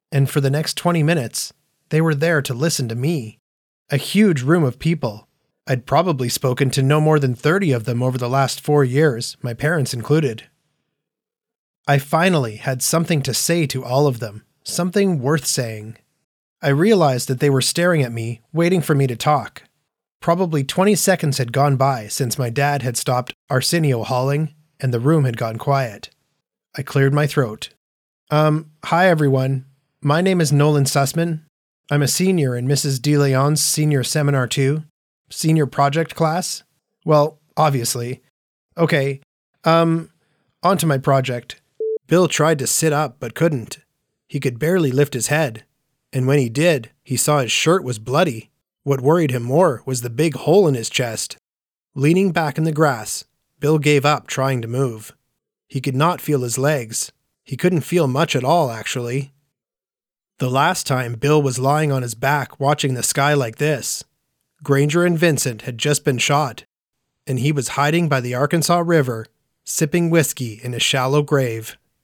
Audiobook Narration
Canadian, American Generic
Studio Specs: Soundproof, professional-grade setup
A young, energetic, and adaptable voice